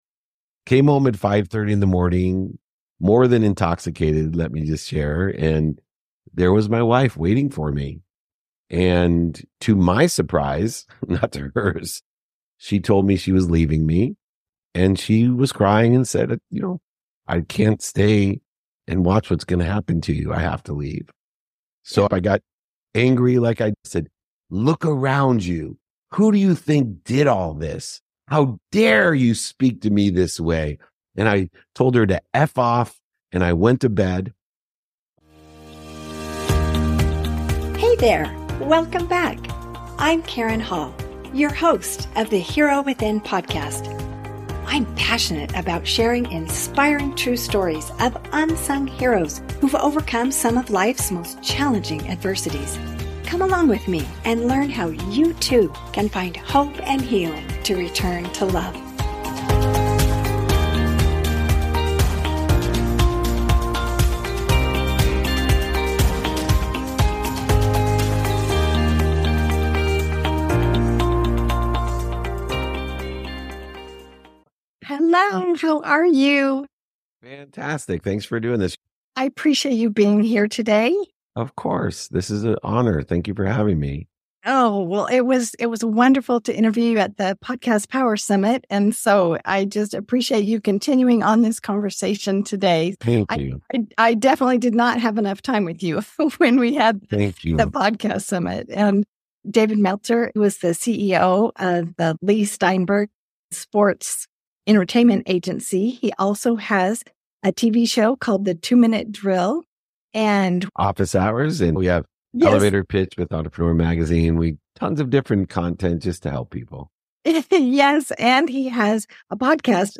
Tune in for an inspiring conversation about faith, love, forgiveness, and the true meaning of happiness and learn about